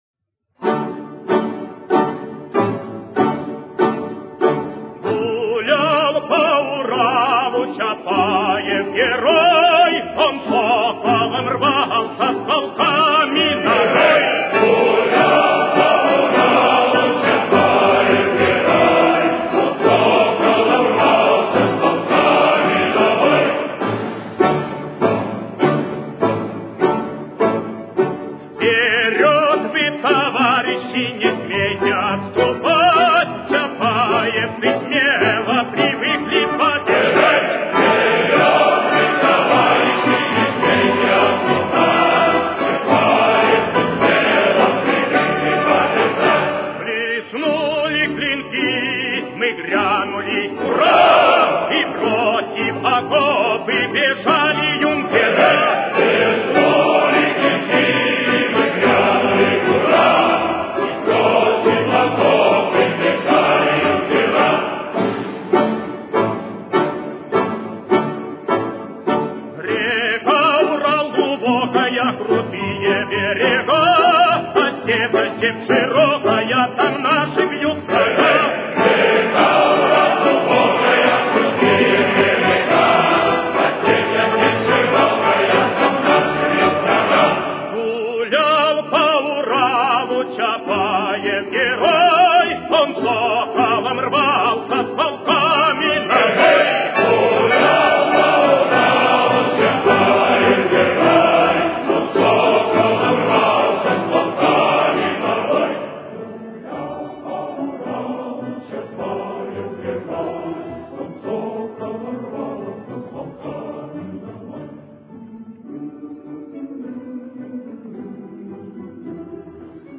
Темп: 109.